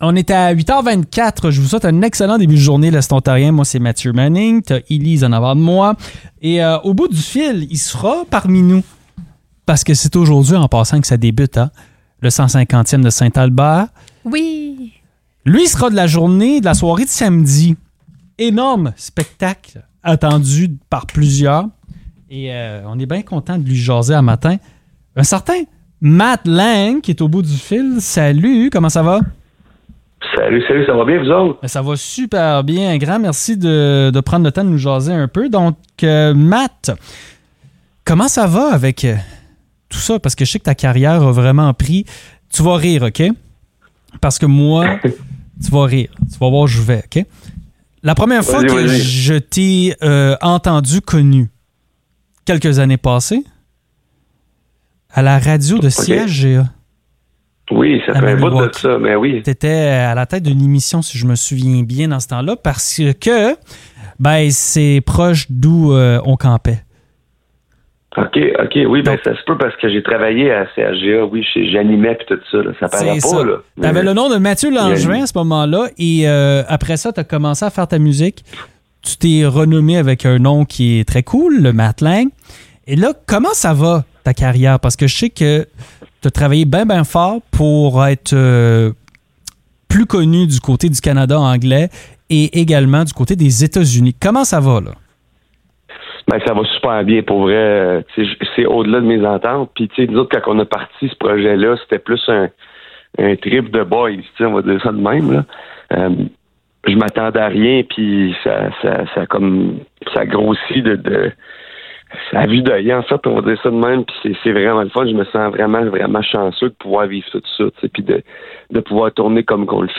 Matt Lang était avec nous ce matin pour jaser du spectacle qu'il donnera samedi soir à St-Albert dans le cadre du festival du 150e.